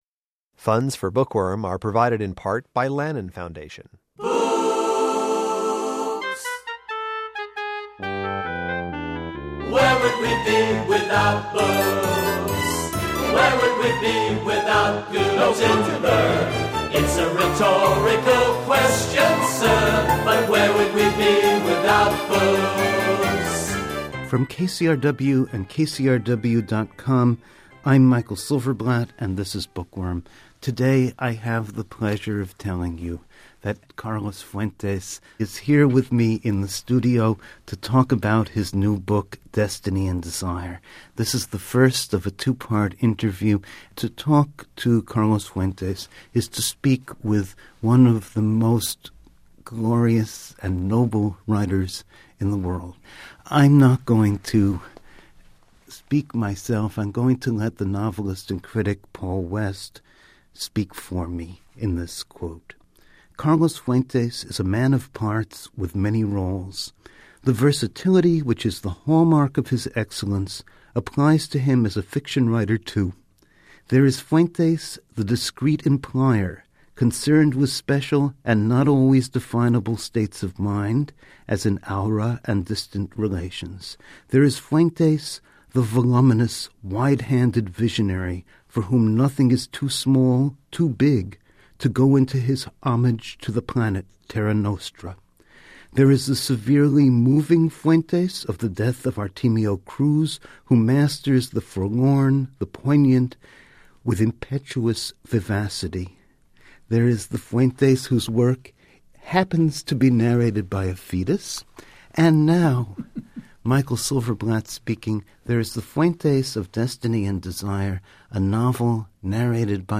Destiny and Desire (Random House) The great Mexican writer modestly confides that yes, he has completed a new novel but it's really the same story, just with new characters... (Part I of this two-part conversation airs on March 24.)